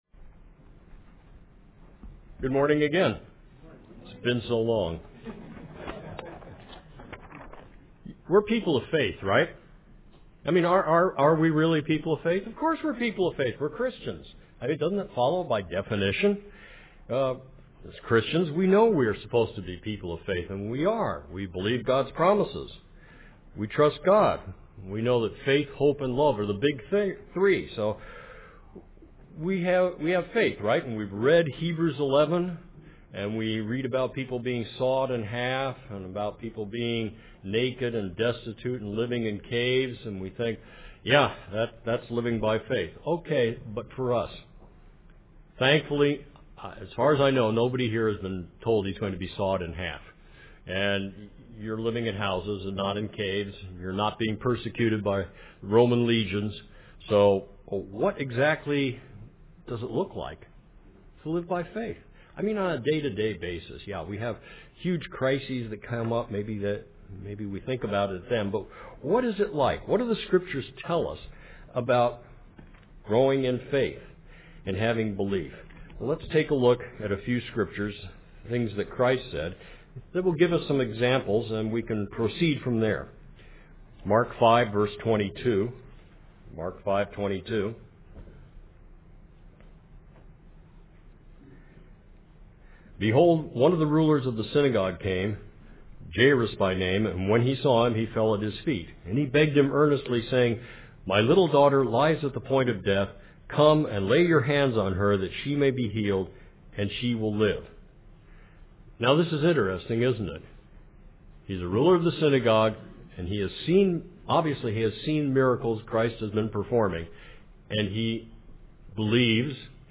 UCG Sermon Notes